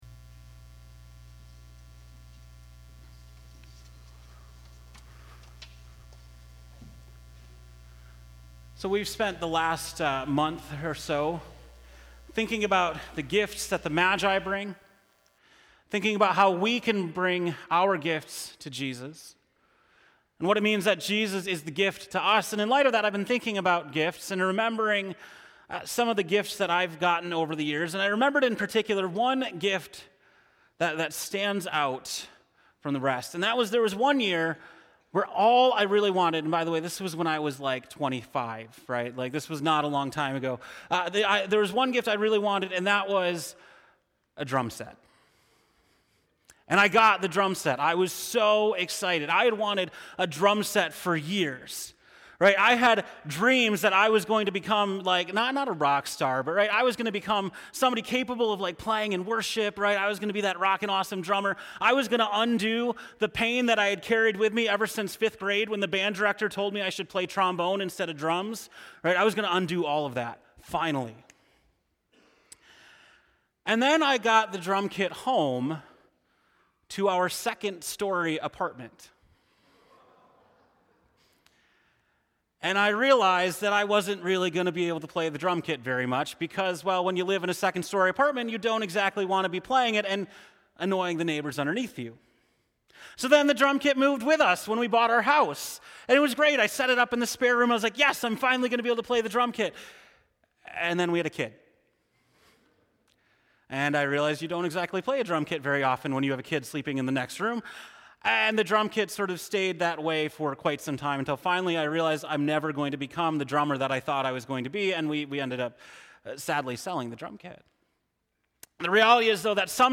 December 29, 2019 (Morning Worship)